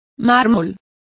Complete with pronunciation of the translation of marbles.